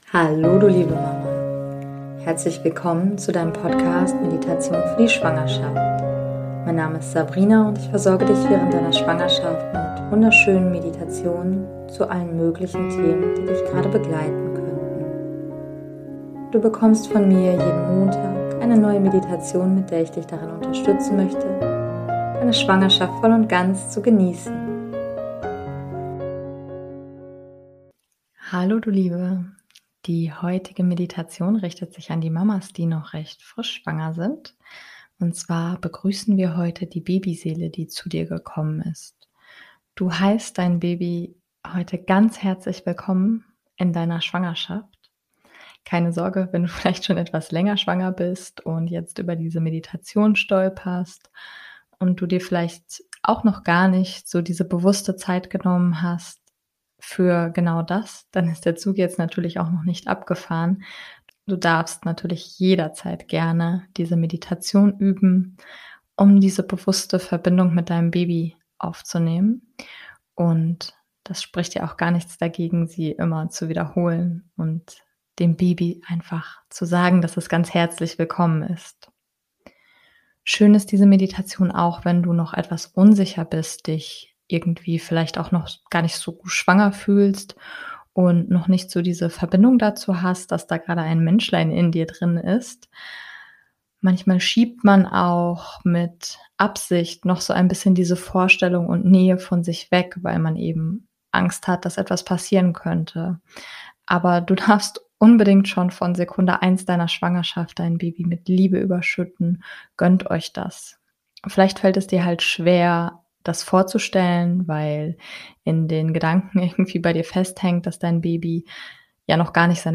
#049 - Heiße dein Baby willkommen - Für die ersten Wochen der Schwangerschaft ~ Meditationen für die Schwangerschaft und Geburt - mama.namaste Podcast